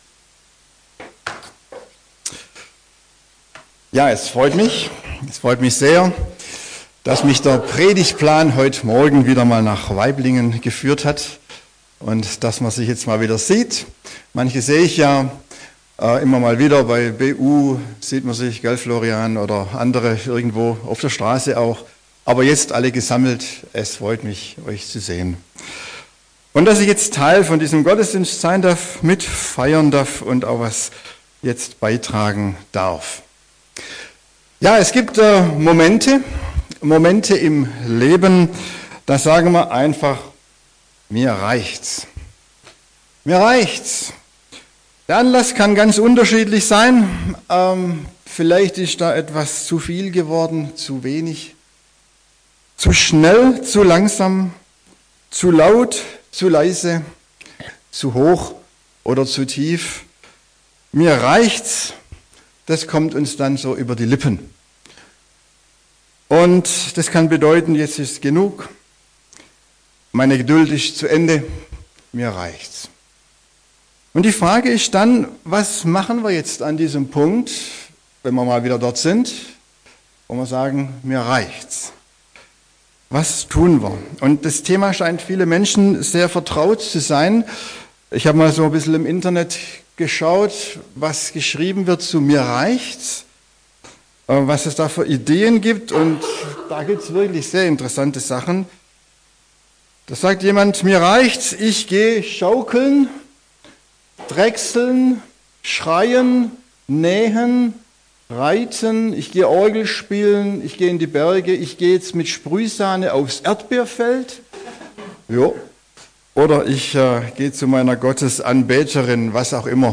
Predigten aus der Fuggi